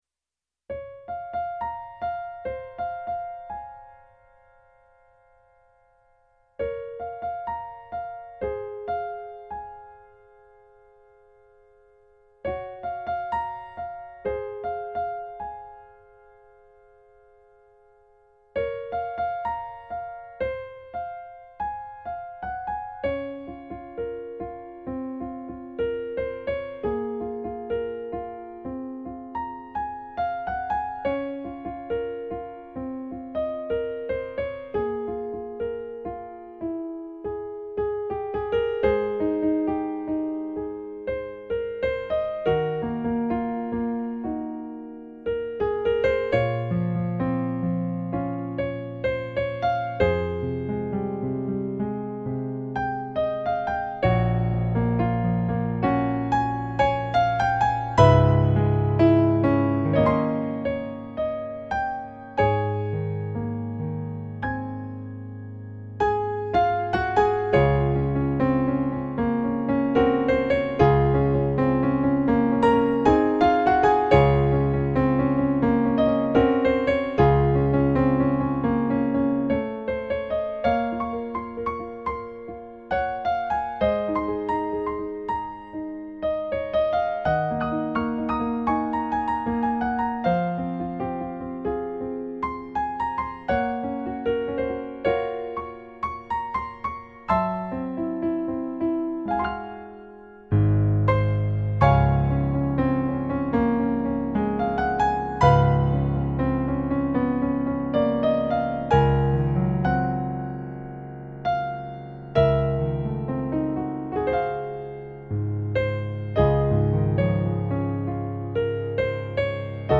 eight piano solo arrangements.  34 pages.
New Age remix